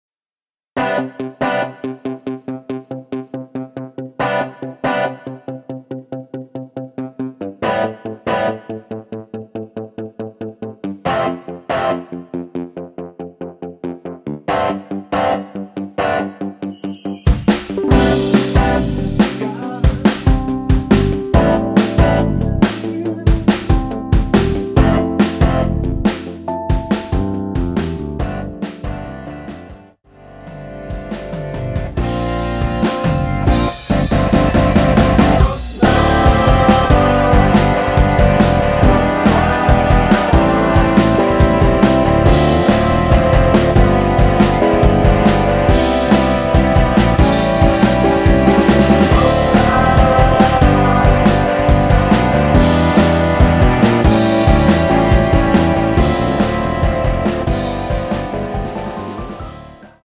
원키에서(-2) 내린 코러스 포함된 MR 입니다.(미리듣기 참조)
앞부분30초, 뒷부분30초씩 편집해서 올려 드리고 있습니다.
중간에 음이 끈어지고 다시 나오는 이유는